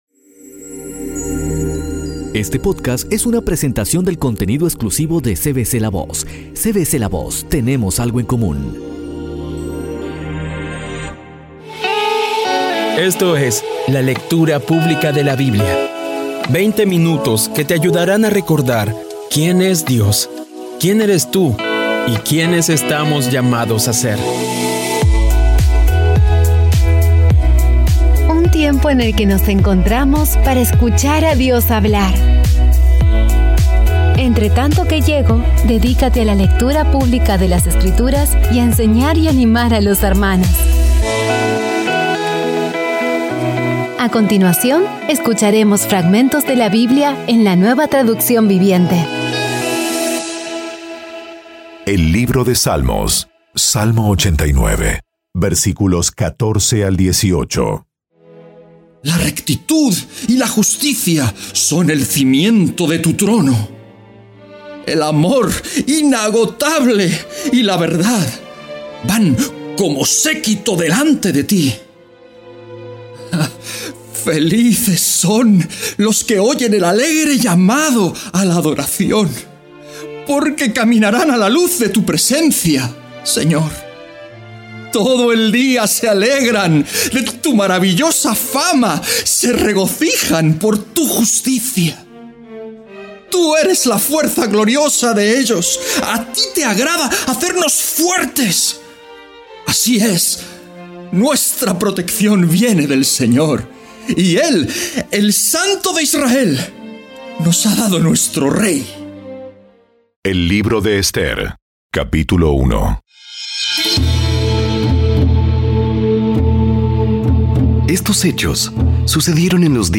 Sigue usando esta maravillosa herramienta para crecer en tu jornada espiritual y acercarte más a Dios. Poco a poco y con las maravillosas voces actuadas de los protagonistas vas degustando las palabras de esa guía que Dios n...